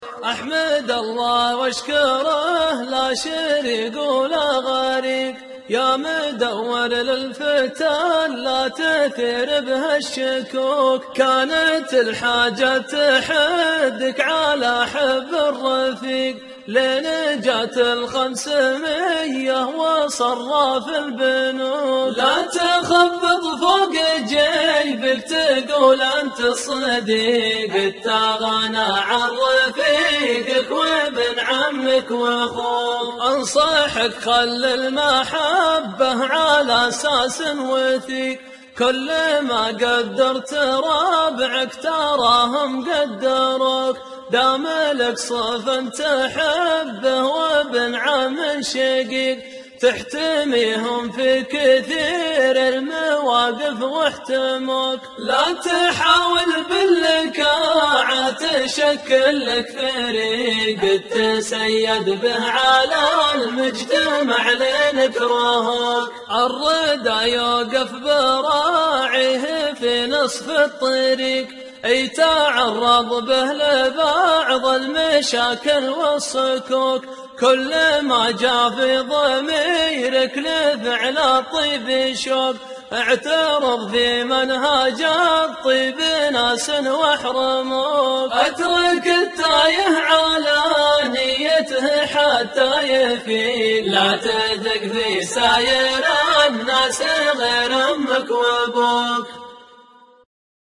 شيلة جديد 2014